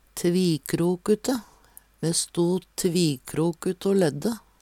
tvikrokute - Numedalsmål (en-US)
Tilleggsopplysningar gradbøygning Hør på dette ordet Ordklasse: Adjektiv Kategori: Karakteristikk Attende til søk